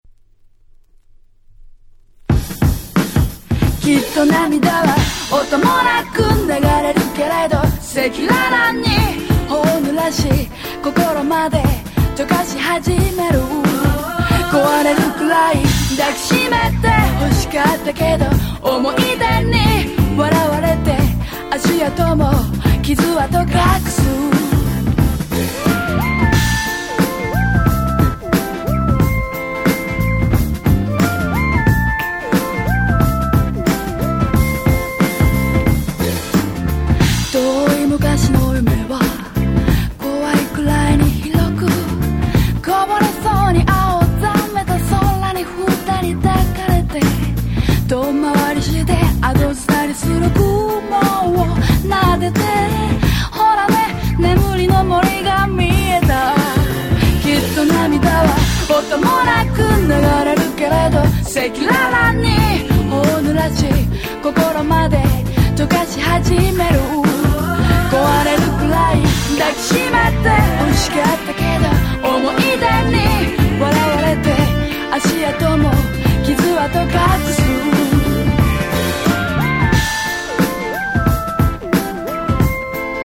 (試聴ファイルは別の盤から録音してあります。参考程度にお考えください。)
96' Japanese R&B 超名盤を2017年に初7''化した1枚。